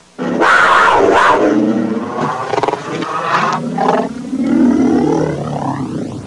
Cougar Attack Sound Effect
Download a high-quality cougar attack sound effect.
cougar-attack-1.mp3